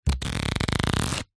Звуки игральных карт
Пальцами перебираем колоду